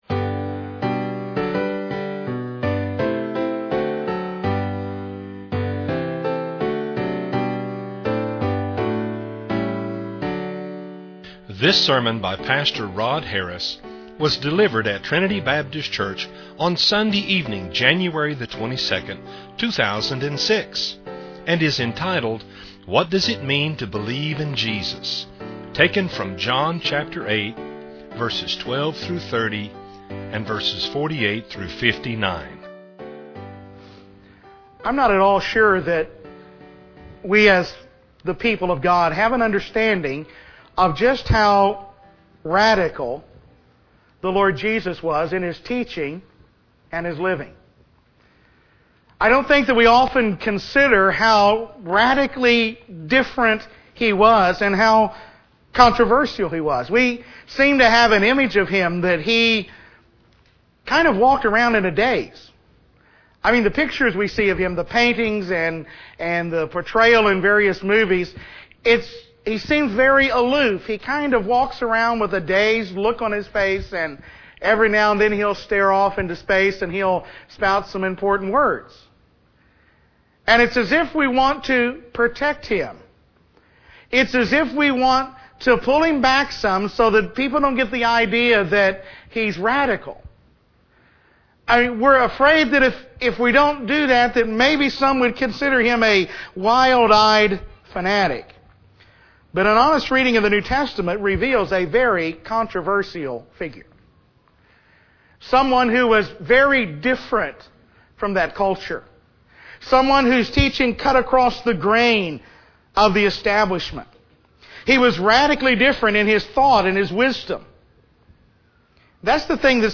This is an exposition of John 8:12-30, 48-59.